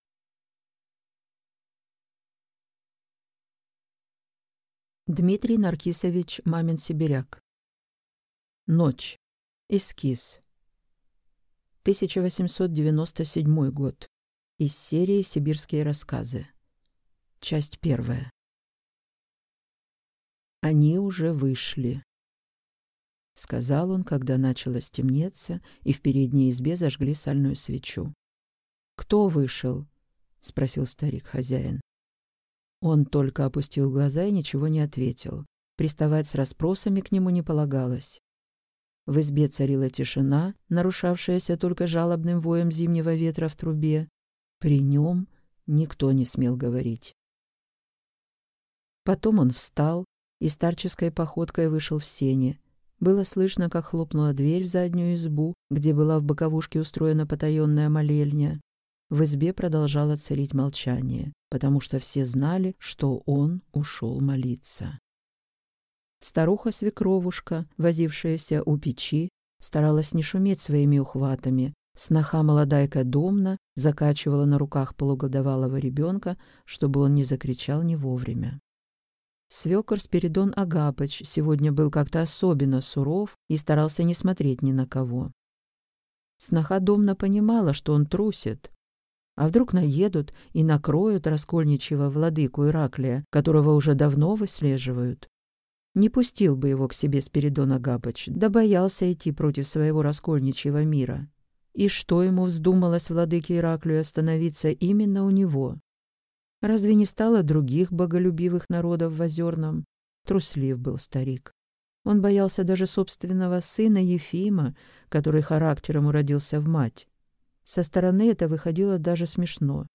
Аудиокнига Ночь | Библиотека аудиокниг